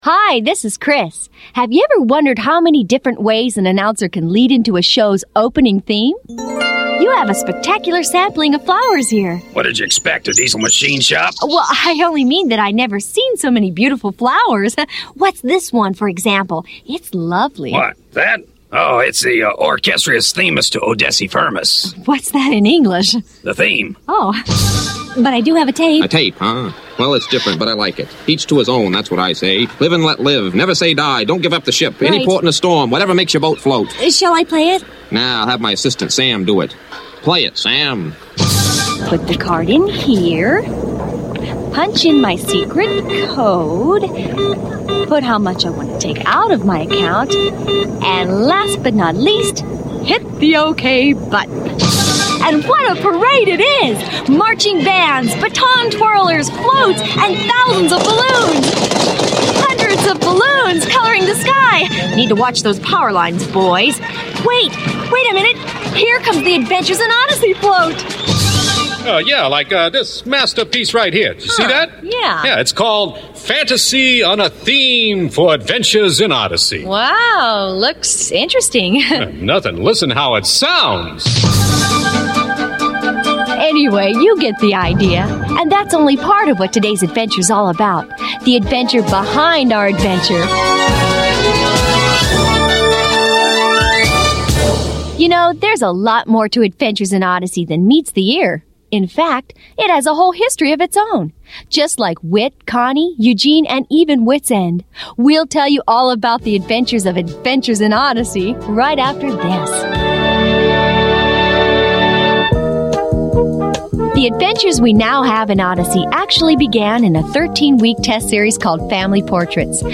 Created for ages 8-12 but enjoyed by the whole family, Adventures in Odyssey presents original audio stories brought to life by actors who make you feel like part of the experience. These fictional, character-building dramas are created by an award-winning team that uses storytelling to teach lasting truths.